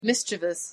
A teraz zobacz i posłuchaj wymowy:
Gdyby teraz skrupulatnie policzyć sylaby wyszłyby nam tylko trzy. Zatem psotność tego słowa kryje się głównie w tym jak się je wymawia, gdyż udaje więcej sylab niż naprawdę ma.
pronunciation_en_mischievous.mp3